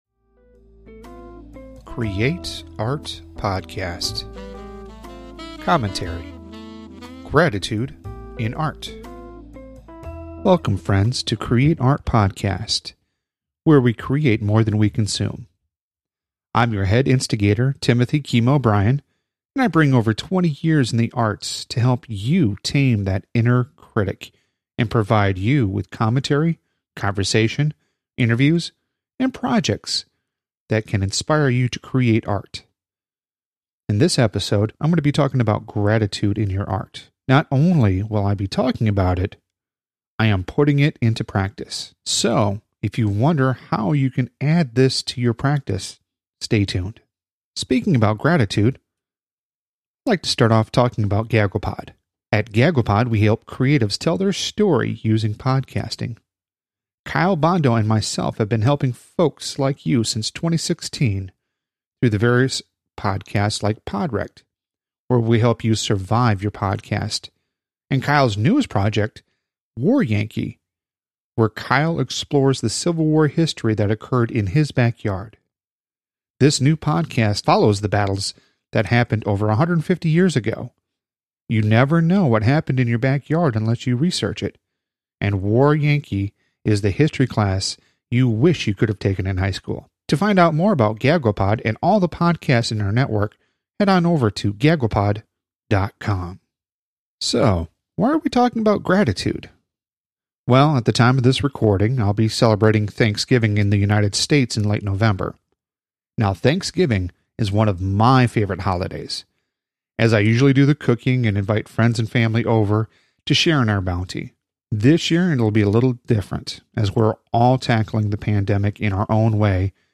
Commentary : Gratitude in Art